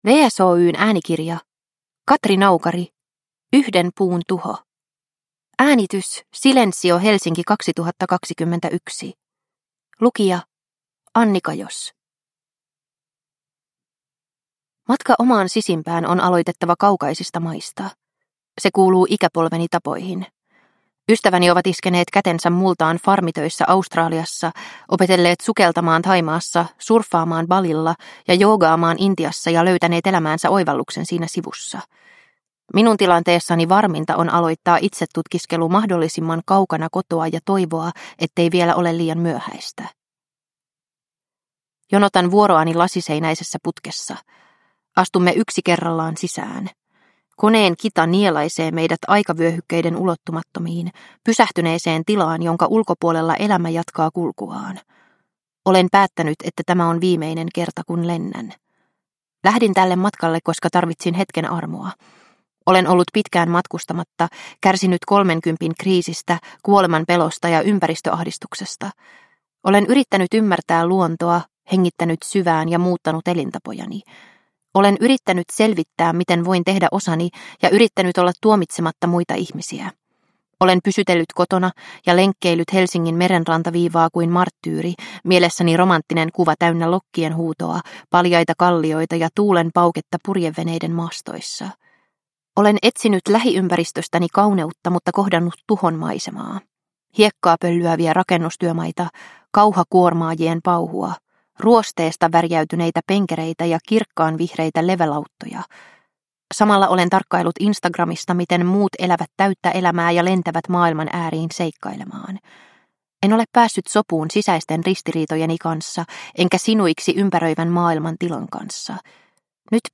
Yhden puun tuho – Ljudbok